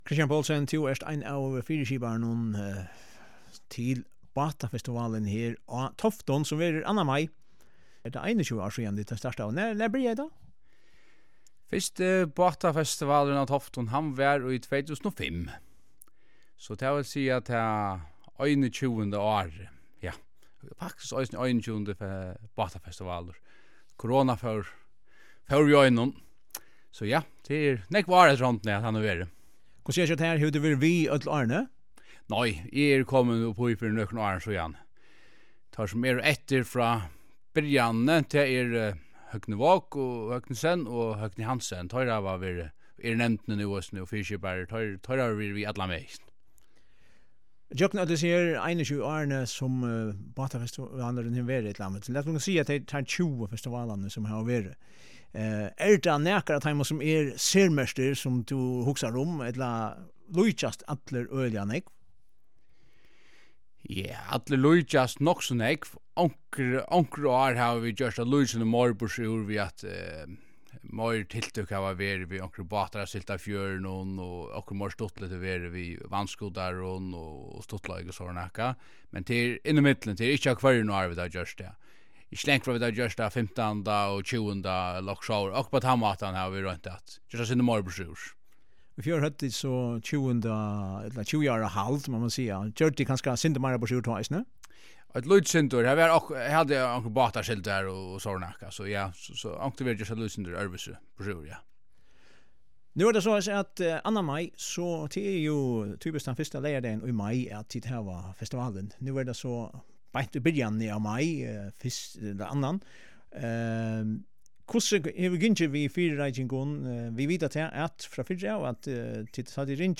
samrøðuna